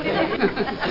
Giggles Sound Effect
Download a high-quality giggles sound effect.
giggles.mp3